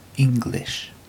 Ääntäminen
IPA : [ˈɪŋ.ɡlɪʃ]